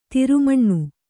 ♪ tiru maṇṇu